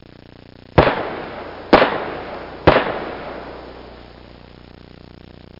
Gunshots Sound Effect
Download a high-quality gunshots sound effect.
gunshots.mp3